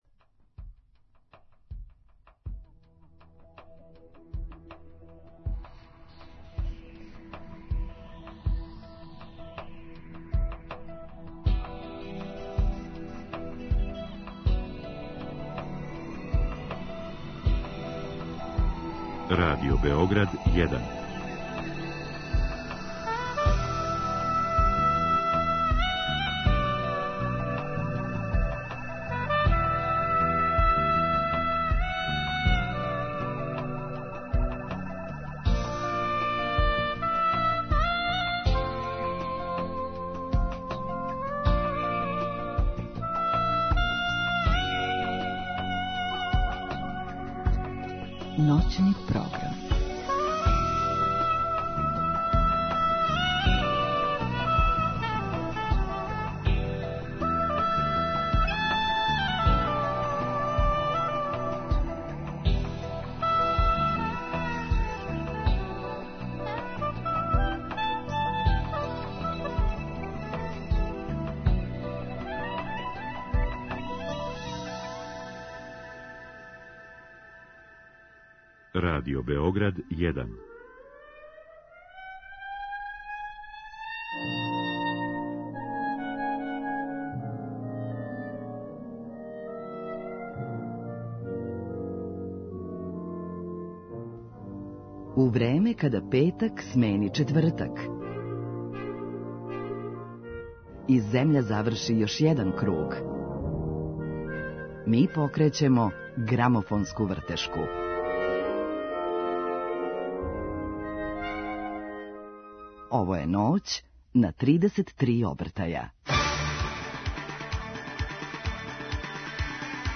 Гост: Никола Чутурило - Чутура